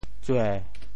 潮州发音 潮州 zuê7
tsue7.mp3